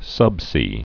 (sŭbsē)